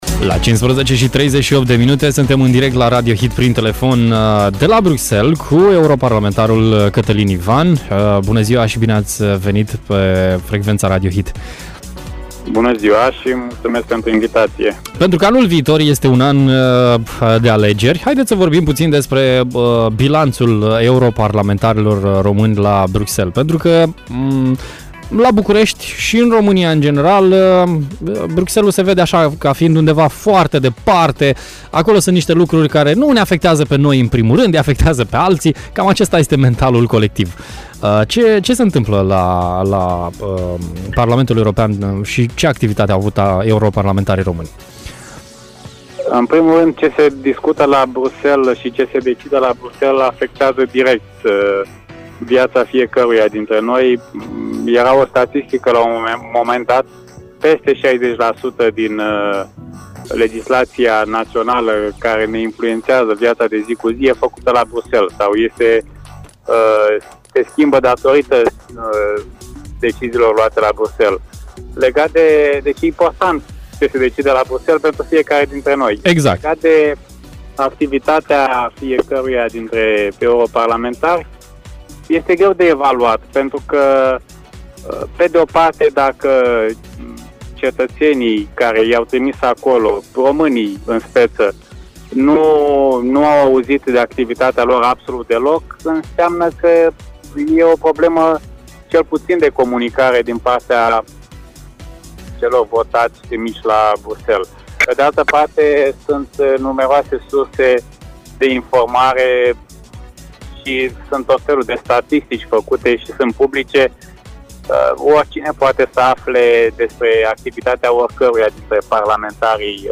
Peste 1 an de zile în România vom avea alegeri pentru Parlamentul European. Ce fac europarlamentarii români, ce strategie are euro-parlamentarul Catălin Ivan și cum vede el situația actuală a României am aflat în direct la Radio Hit: